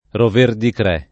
[ roverdikr $+ ]